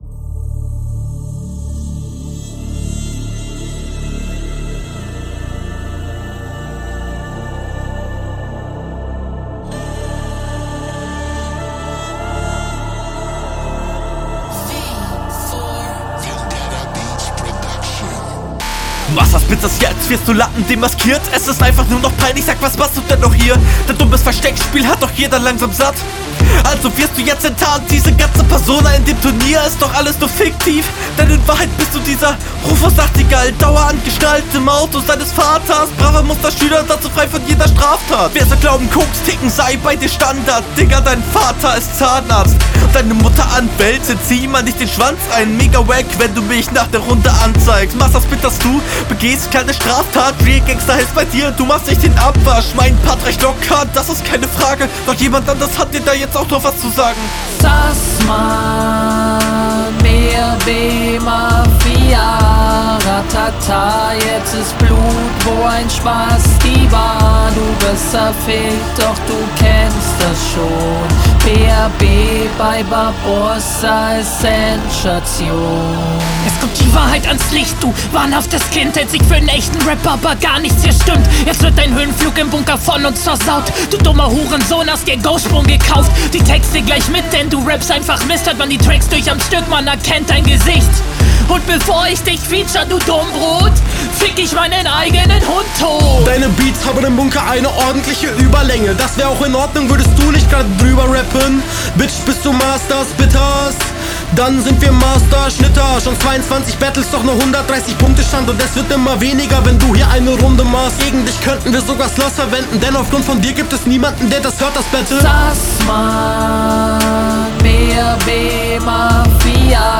Mir gefällt diesmal der aggressive Stimmeinsatz, aber der Flow hat einige Timingfehler und komisch gesetzte …